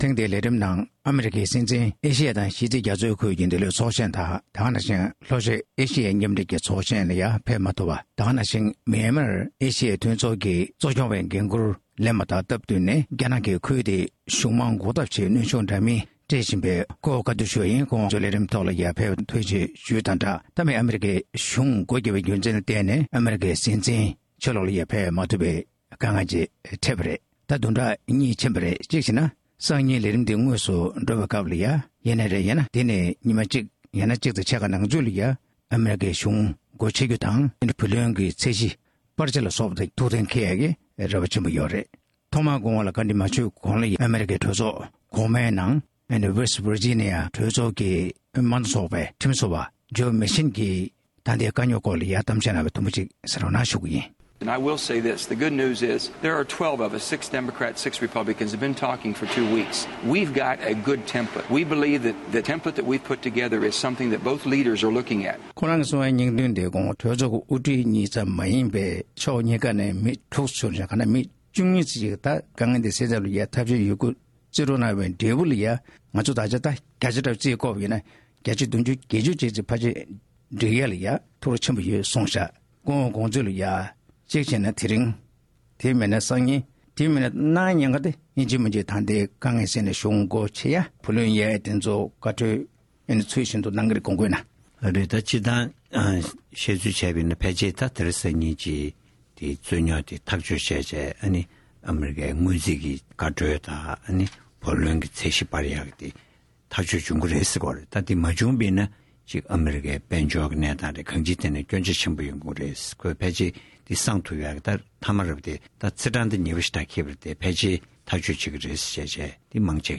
གླེང་མོལ་ཞུས་པར་གསན་རོགས།།